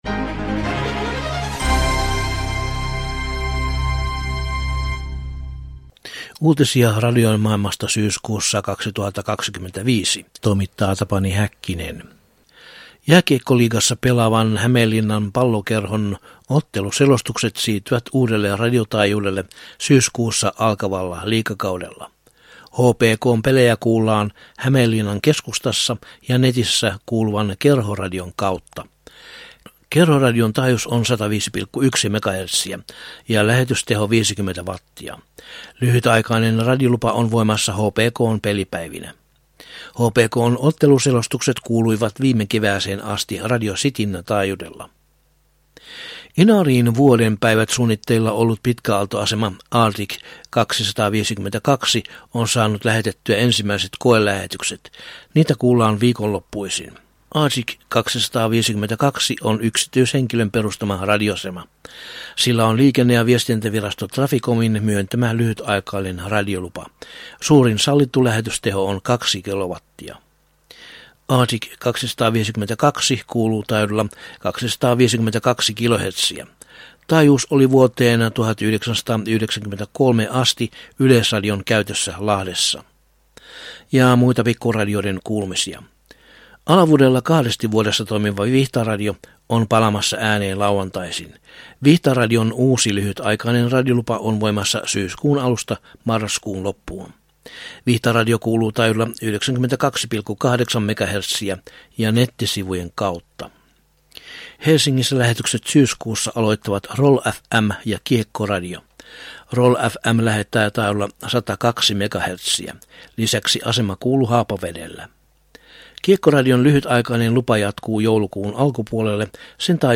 Syyskuun 2025 uutislähetyksessä aiheina ovat muun muassa Arctic 252:n koelähetysten alkaminen Inarissa, BBC:n pitkäaaltolähetysten päättyminen vuoden kuluttua ja Romanian yleisradion säästötoimet keskipitkillä aalloilla.